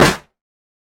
Snare 014.wav